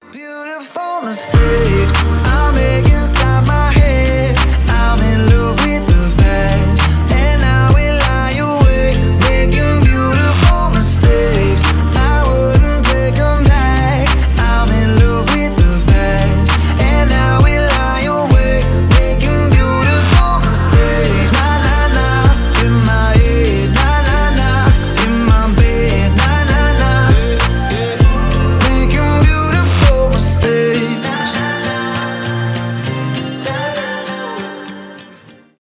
rnb
pop rock